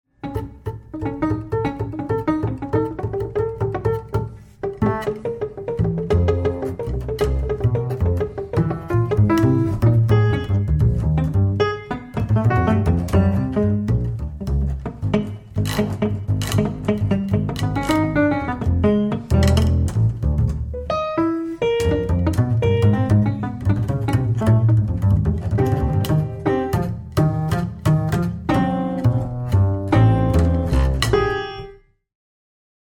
at Secret House Studio, Amsterdam
contrabajo
piano preparado